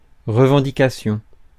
Ääntäminen
IPA: /ʁə.vɑ̃.di.ka.sjɔ̃/